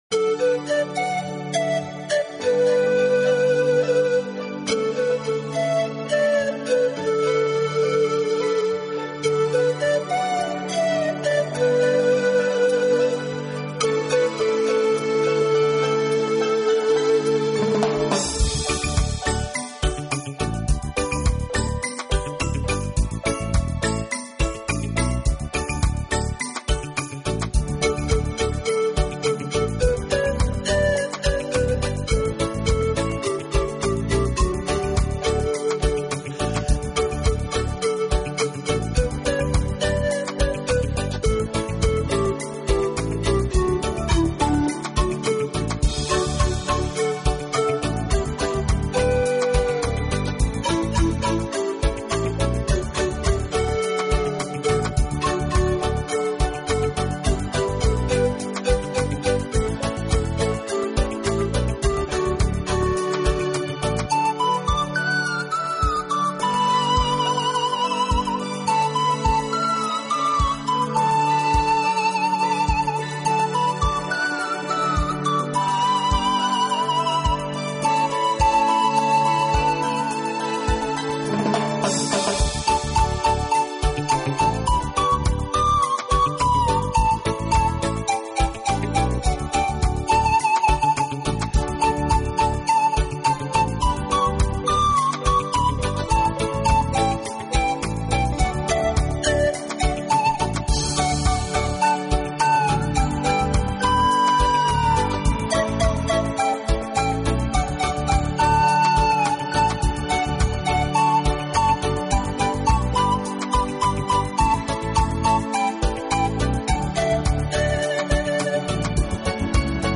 Genre: Instrumental